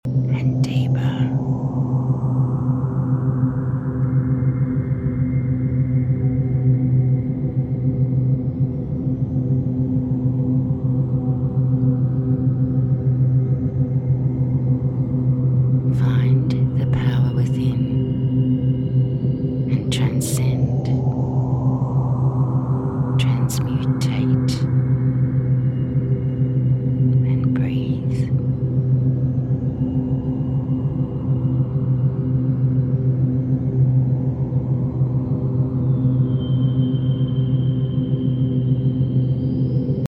Binaural beats work by sending slightly different sound wave frequencies to each ear, guiding your brain into specific states through brainwave frequency entrainment.
trimmed-trancendetal-theta-binaural-beat.mp3